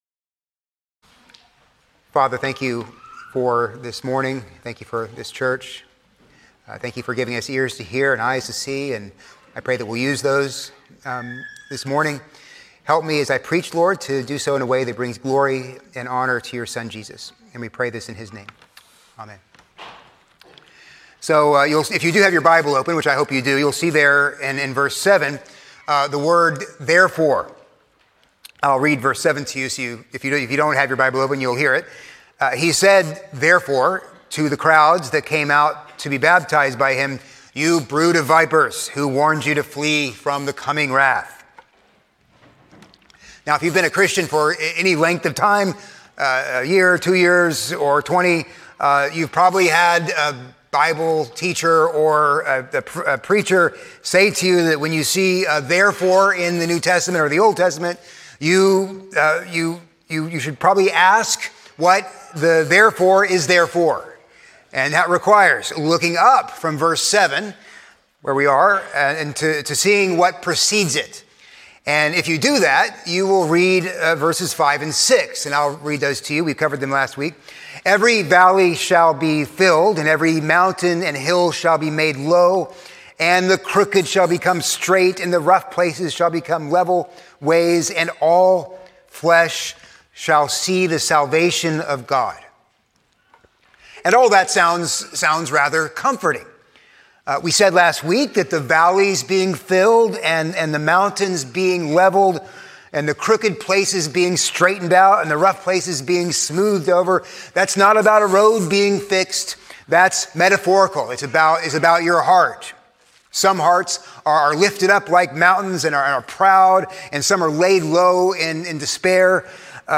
A sermon on Luke 3:7-20